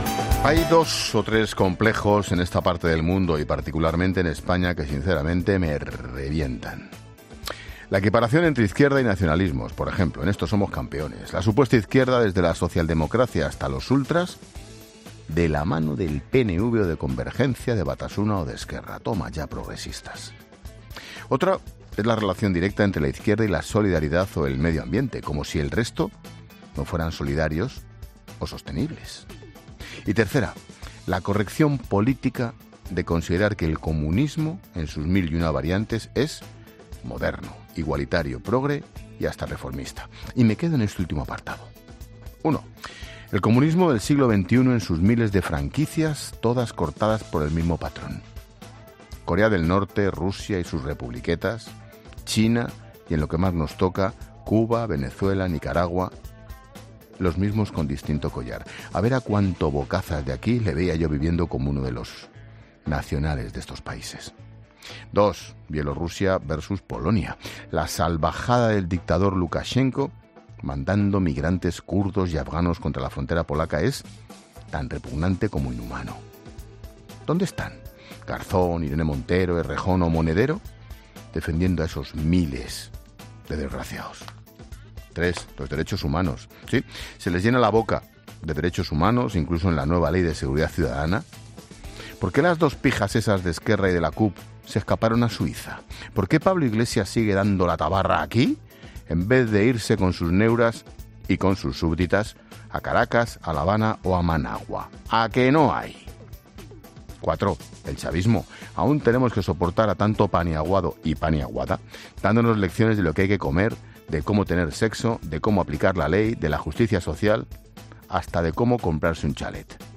Monólogo de Expósito
El director de 'La Linterna', Ángel Expósito, reflexiona sobre las principales noticias de la semana en su monólogo